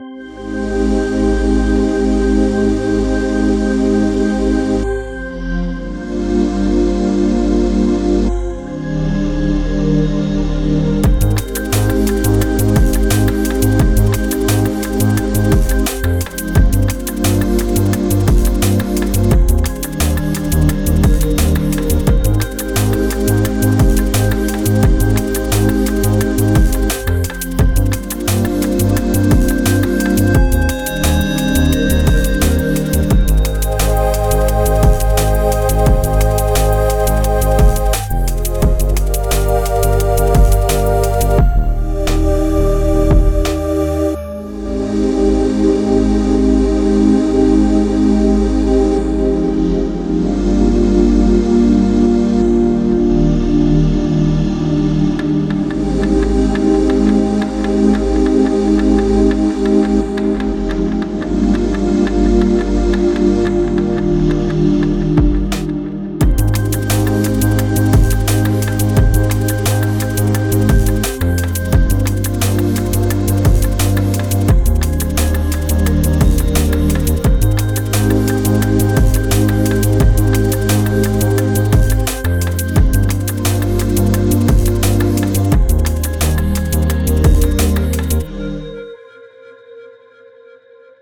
there are several things which point into the halftime liquid dnb labels (if these labels even exist) but then again it feels completely different to (liquid dnb)
I can hear the dnb dna there.
chill, chillout, electronica, downtempo, beats, triphop comes to mind.